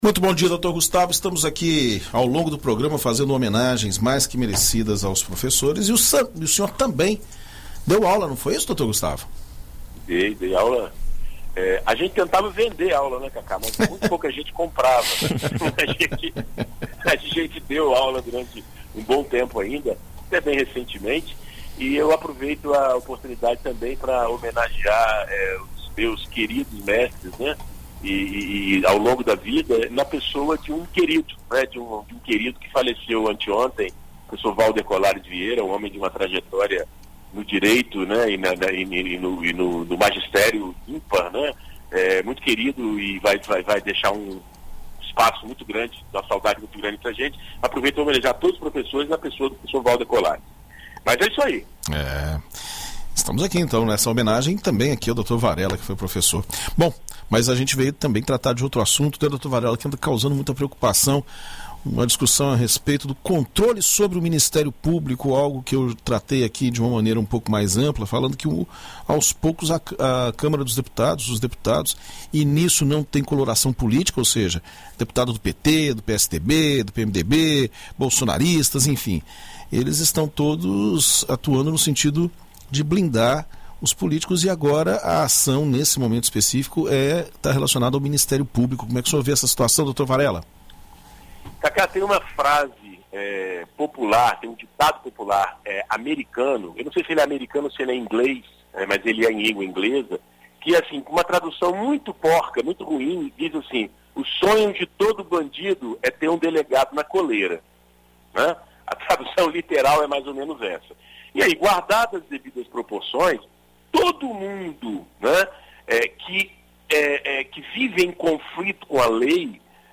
Na coluna Direito para Todos desta sexta-feira (15), na BandNews FM Espírito Santo